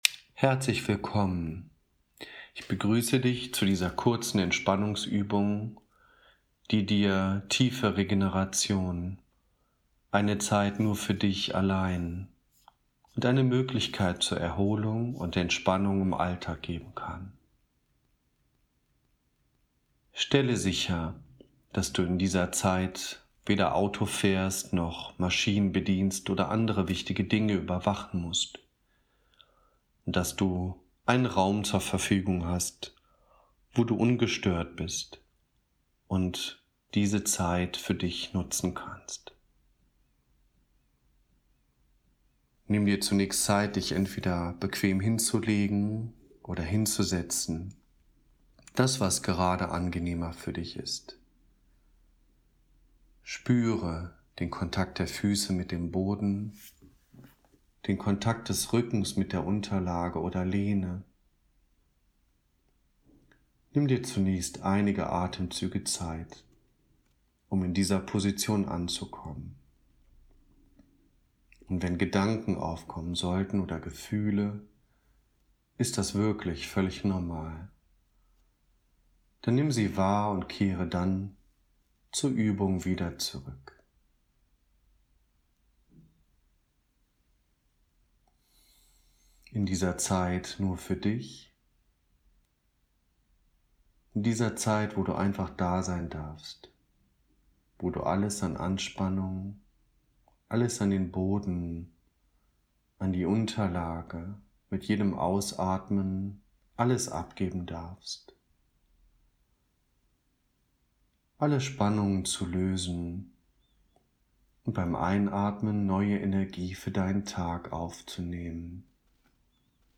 Trance:
blitz-atem-entspannung.mp3